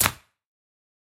Обрезаем секатором стебель цветка